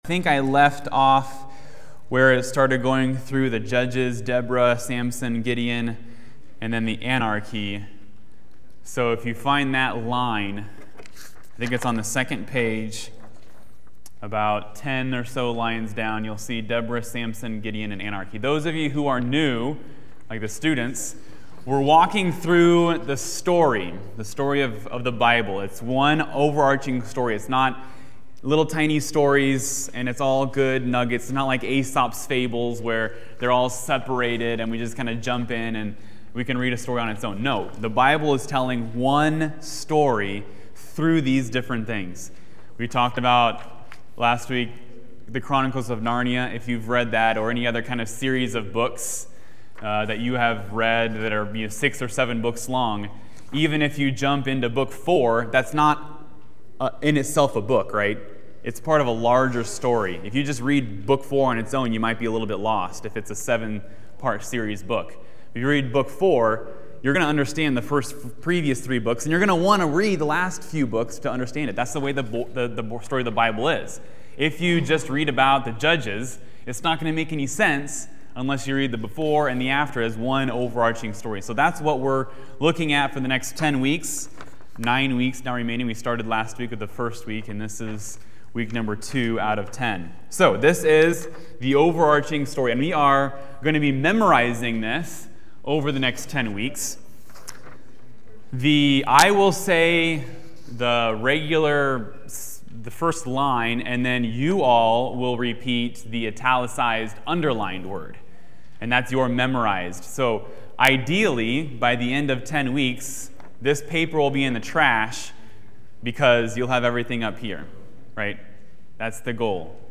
2021 The Story Genesis Transcript In this Adult Sunday School class